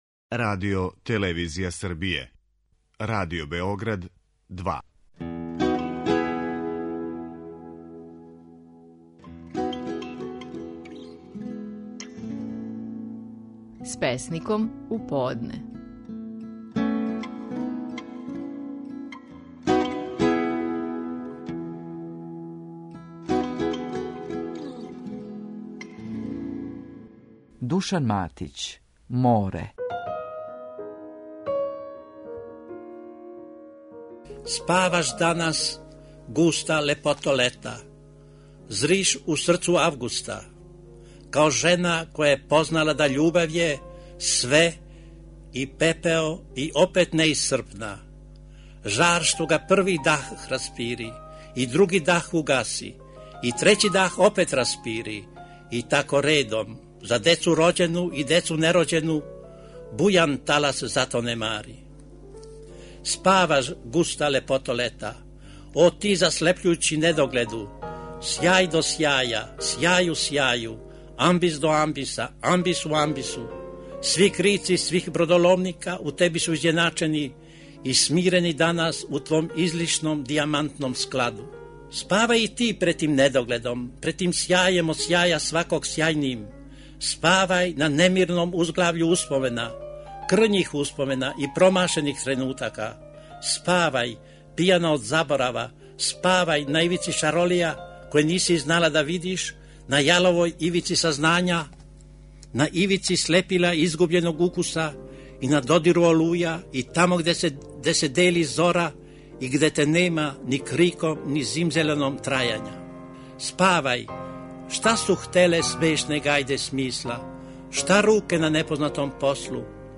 Стихови наших најпознатијих песника, у интерпретацији аутора.
Слушамо Душана Матића и песму „Море".